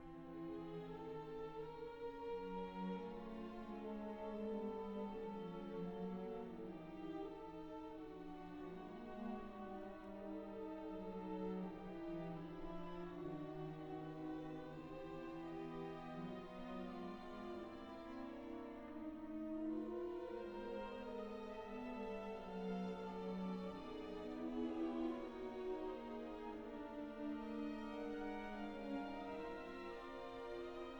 "templateExpression" => "Musique classique"